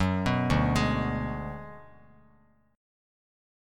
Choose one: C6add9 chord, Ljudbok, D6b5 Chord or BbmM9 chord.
D6b5 Chord